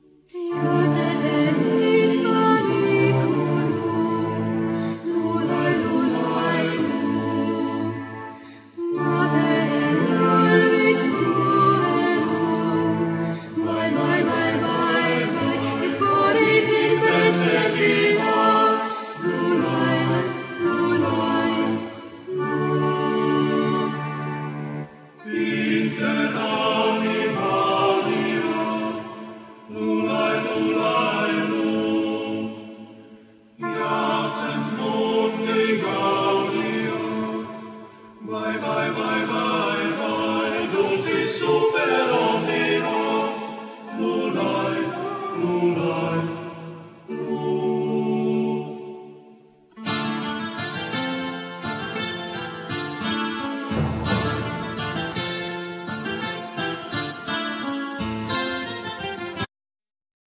Chorus
Keyboards,Sampler,Kokle,Fiddle,Vocal and naration
Guitars
Accoustic guitar
French horn